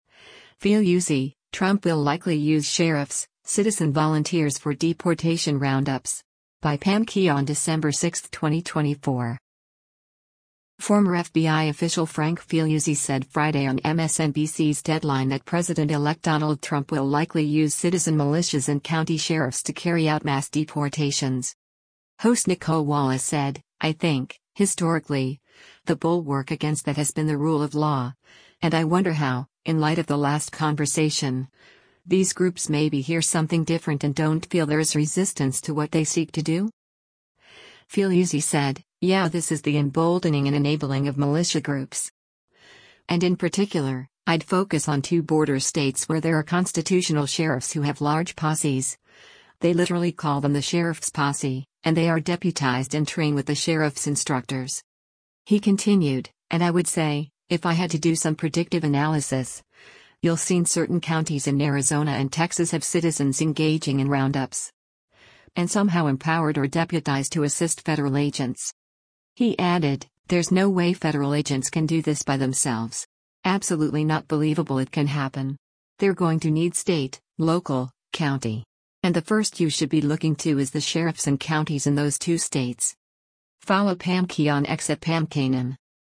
Former FBI official Frank Figliuzzi said Friday on MSNBC’s “Deadline” that President-elect Donald Trump will likely use citizen militias and county sheriffs to carry out mass deportations.